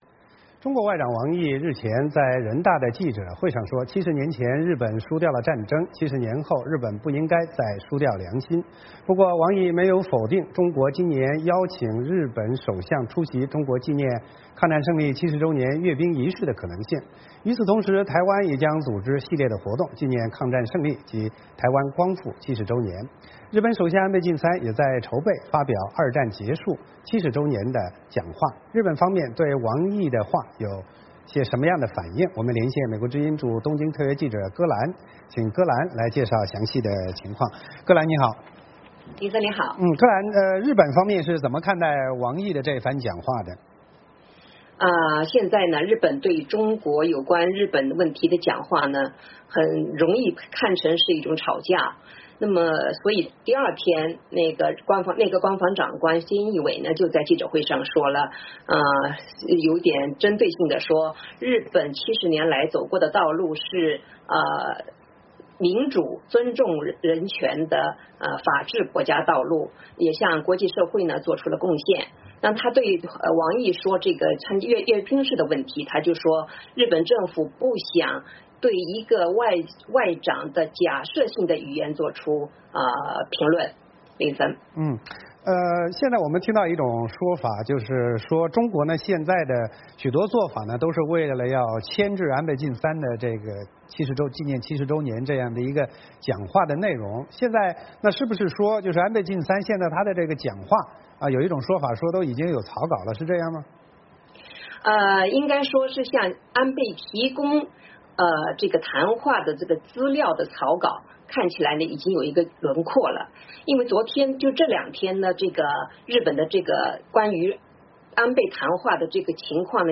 VOA连线：中国或邀日首相出席抗战胜利70周年仪式